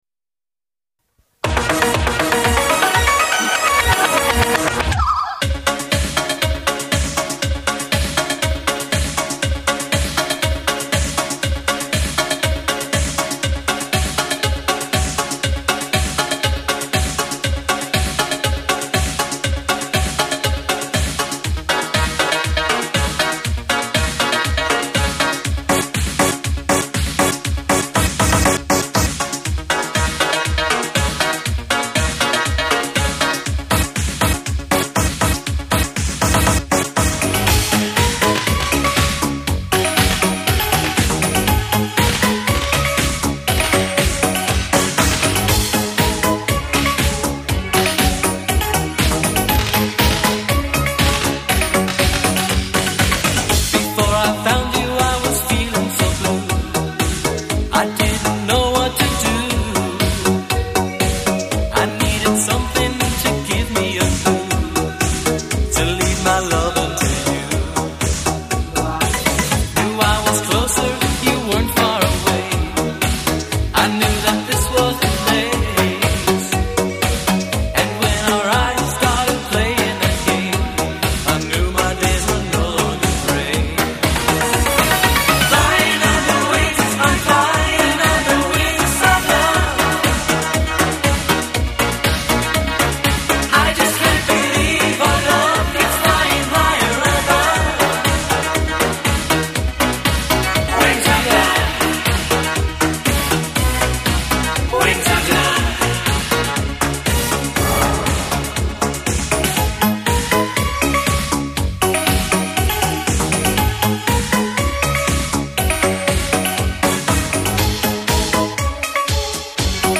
舞曲DJ